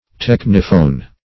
Search Result for " techniphone" : The Collaborative International Dictionary of English v.0.48: Techniphone \Tech"ni*phone\, n. [Gr.